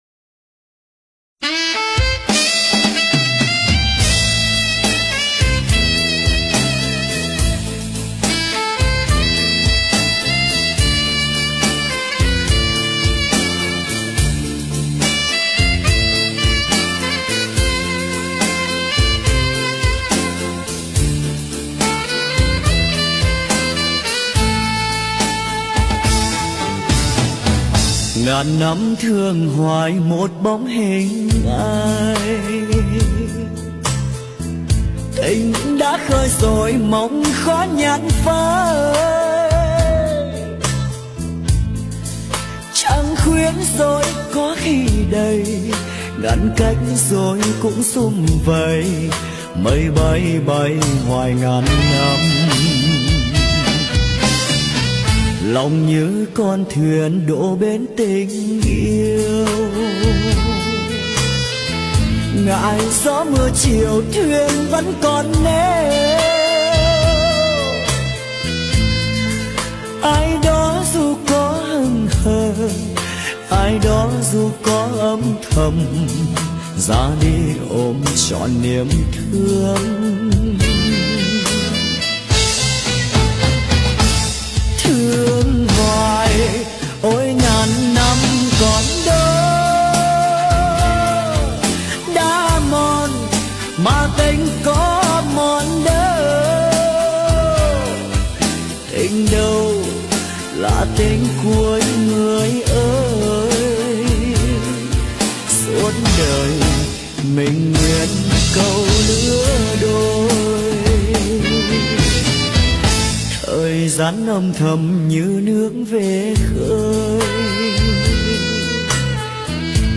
Nhạc Xưa
Nhạc Bolero Trữ Tình